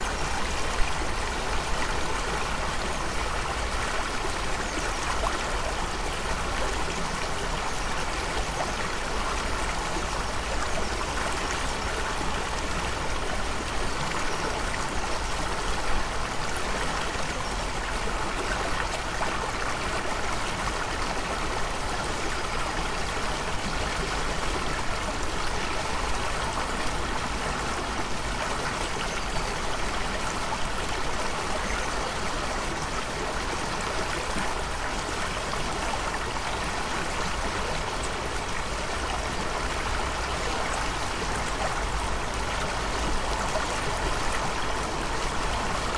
07 氛围；农场；开放空间；汽车经过
描述：农场氛围;性质;鸟类;开放空间;汽车经过距离现场记录环境氛围氛围音景
Tag: 开放的 声景 传球 气氛 环境 通过 氛围 性质 汽车 农场 空间 现场记录 气氛 距离